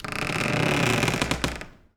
door_A_creak_04.wav